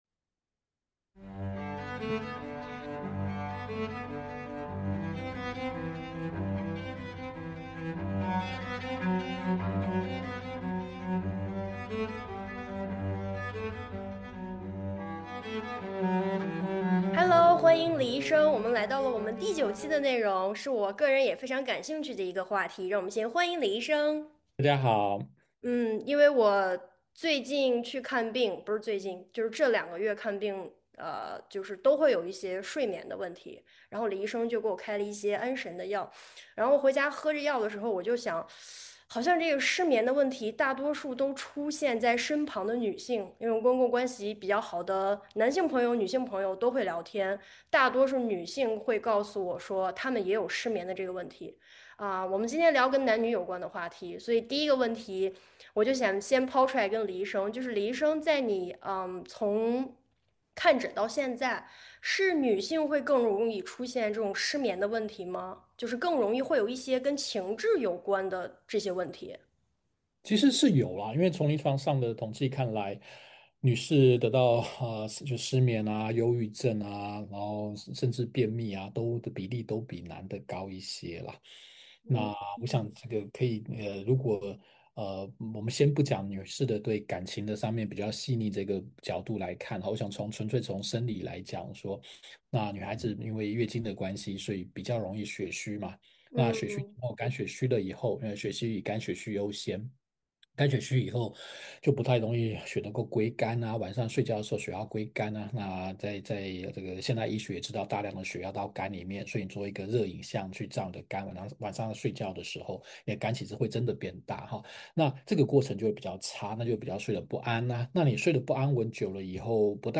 目前沒有特定的題材，也沒有特定的時間表，隨性也隨時間，藉由主持人的提問，來和大家聊一聊。